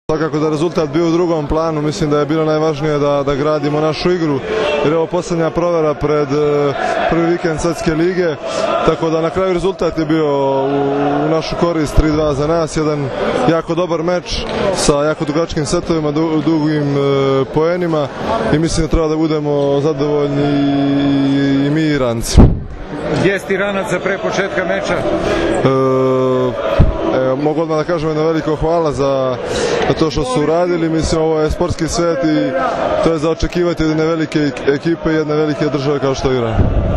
IZJAVA NEMANJE PETRIĆA